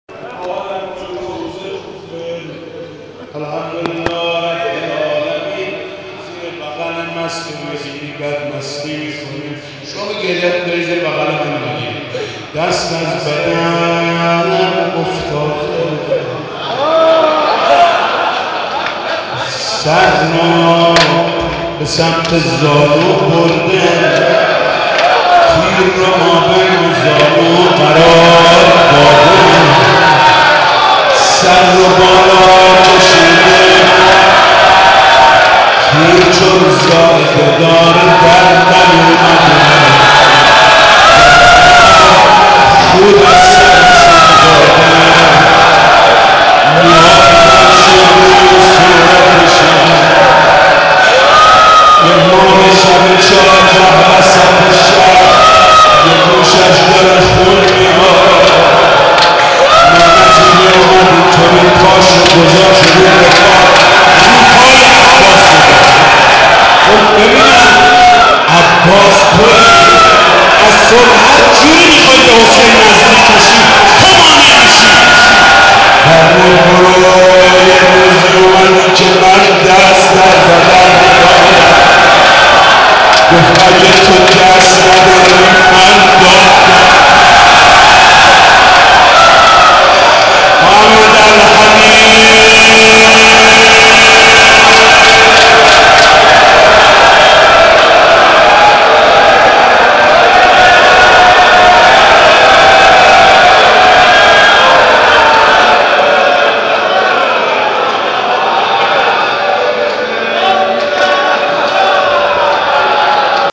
مداحی شب سی ام ماه مبارک در حسینیه دلریش
در این مراسم پرشور که با حضور جمع زیادی از شب زنده داران و عاشقان اهل بیت(ع) برگزار شد حاج محمود کریمی با روضه حضرت قمر بنی هاشم حال و هوای دیگری به مجلس داد.
بخش اول - حاج محمود کریمی - روضه